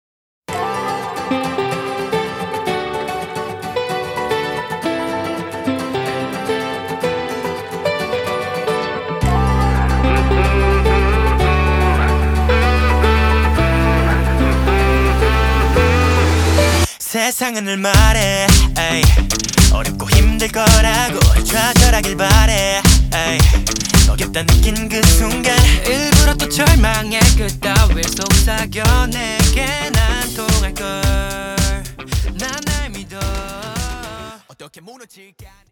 • Качество: 320, Stereo
корейские
K-Pop